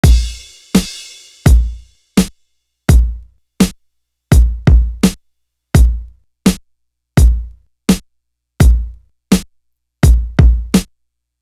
Guns Is Razors Drum.wav